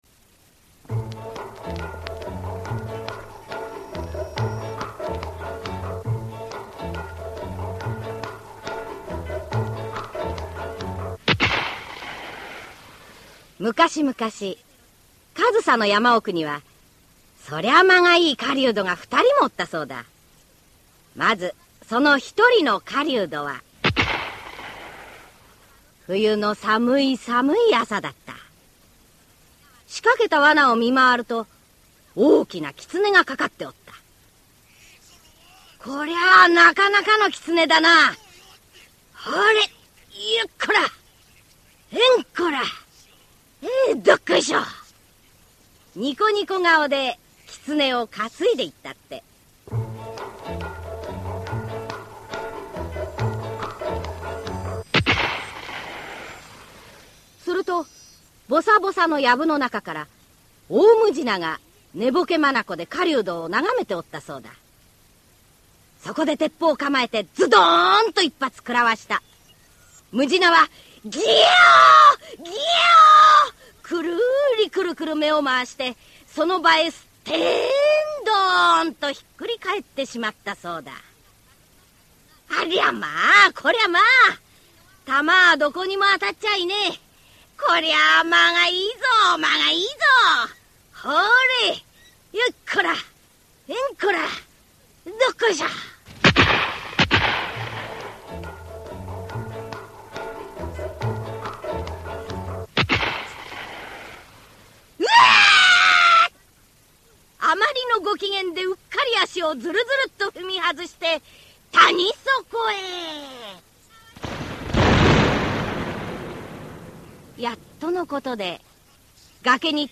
[オーディオブック] 上総のかもとり